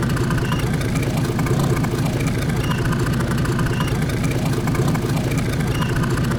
Bigboatidle
BigBoatIdle.wav